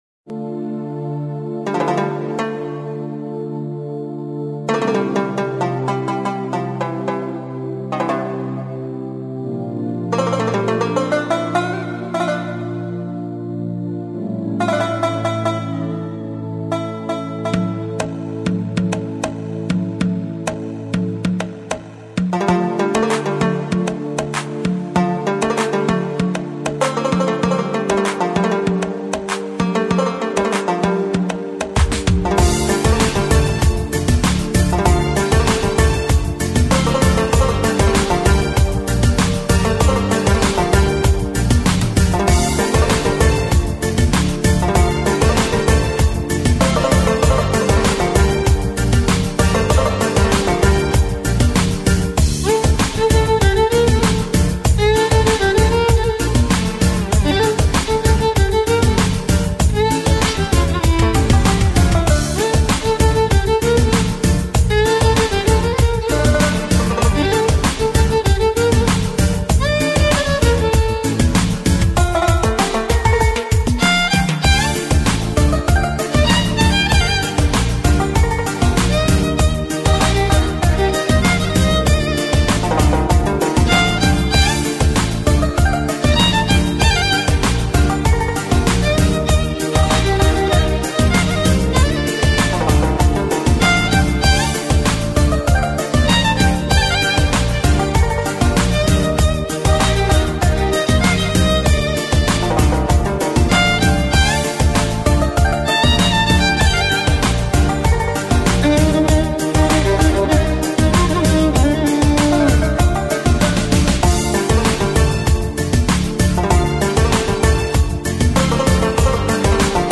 Жанр: Deep House, Organic House